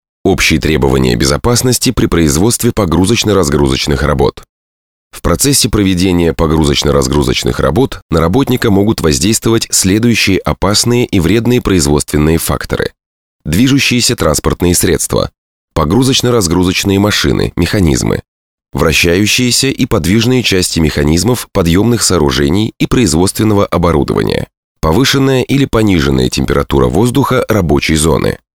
Тракт: Микрофон - Shure SM7B Пульт - Yamaha MX12/4 Карта - M-Audio Delta 10/10lt